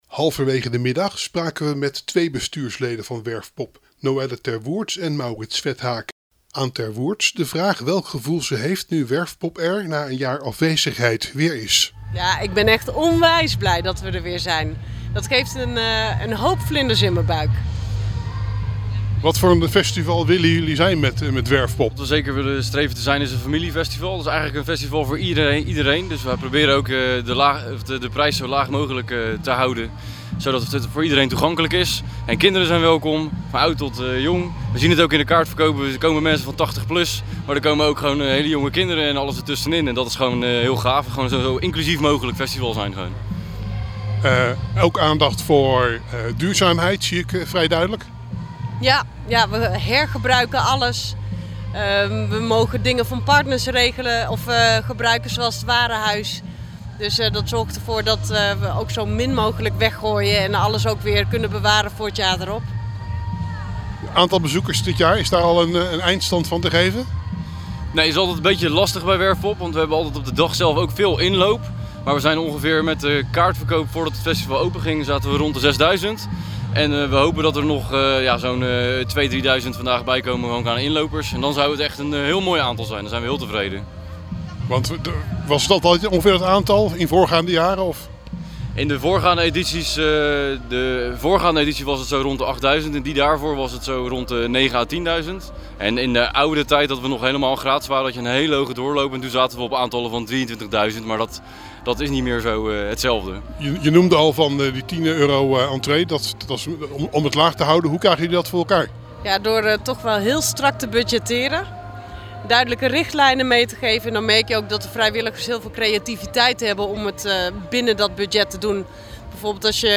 Verslaggever
in gesprek